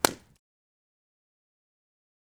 SFX_globoFalla.wav